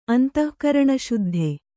♪ antahkaraṇaśuddhe